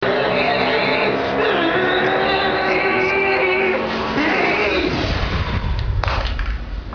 cartoony sound effects.
– Cartoon car sounds
roadrunner.wav